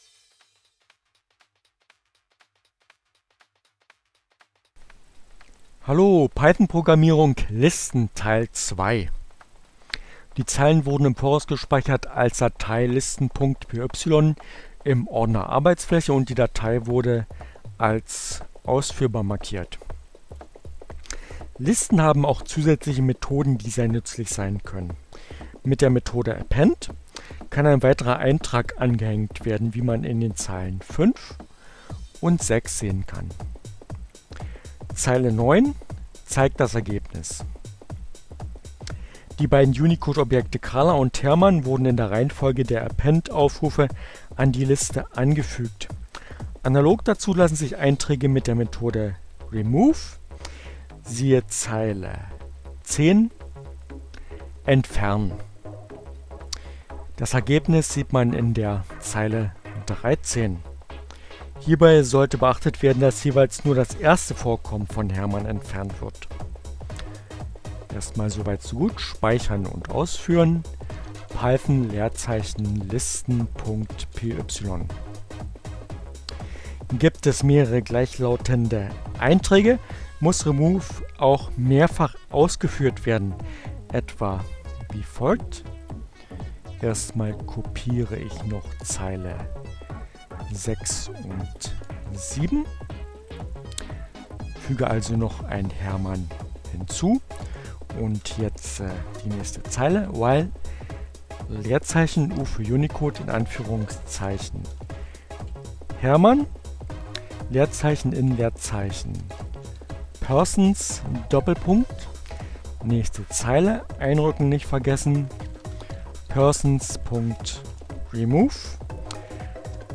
Tags: CC by-sa, Gnome, Linux, Neueinsteiger, Ogg Theora, ohne Musik, screencast, ubuntu, Python, Programmierung, listen